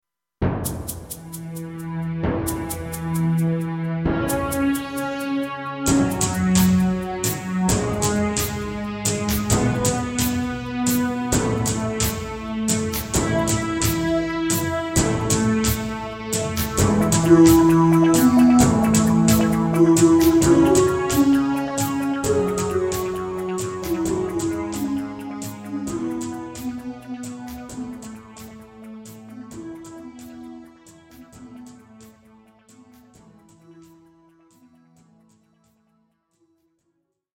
KARAOKE/FORMÁT:
Žánr: Rock